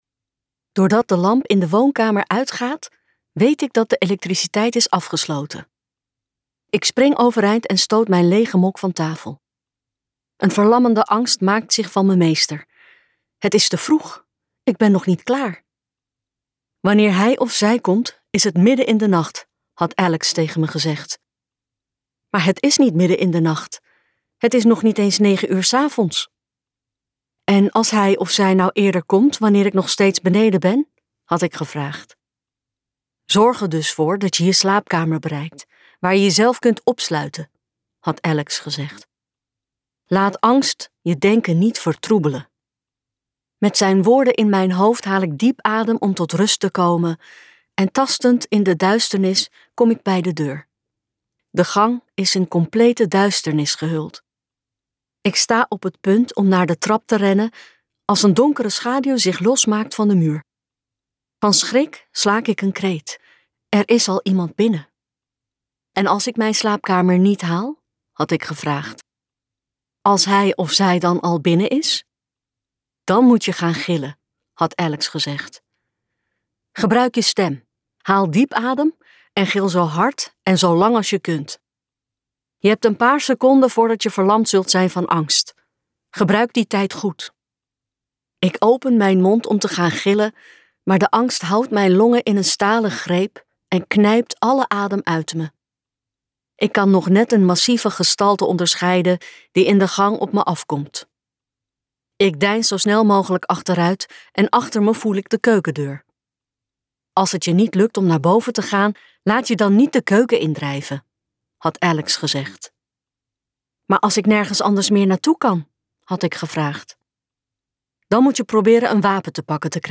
Ambo|Anthos uitgevers - Slaap zacht luisterboek